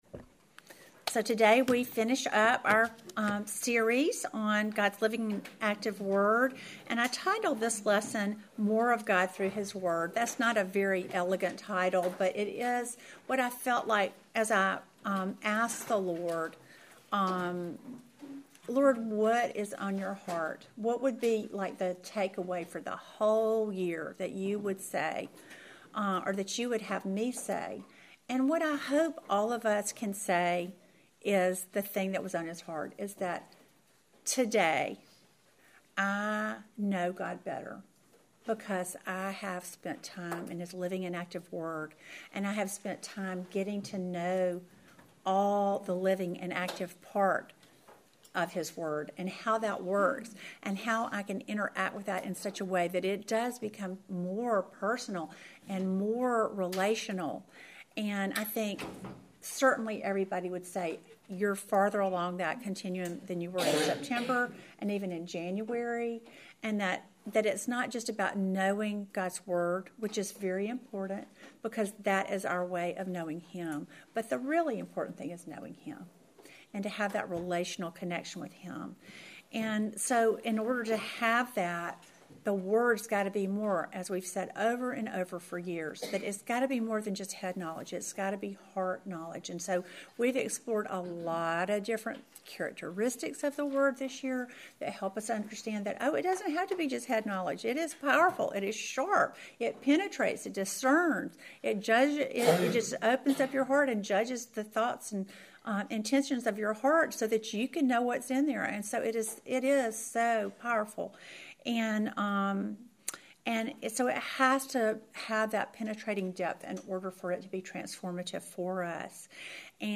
Welcome to the seventeenth and last lesson in our series GOD’S LIVING AND ACTIVE WORD!